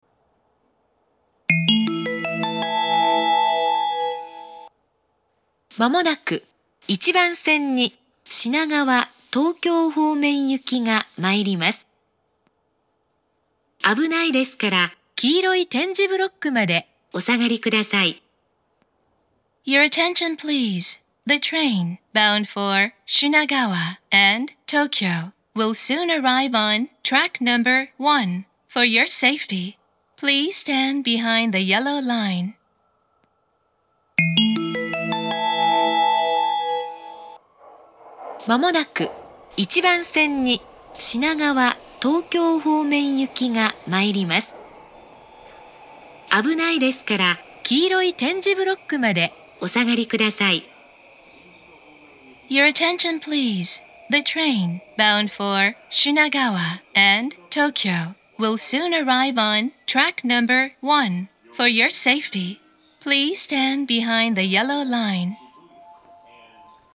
１番線接近放送
meguro1bansen-sekkin3.mp3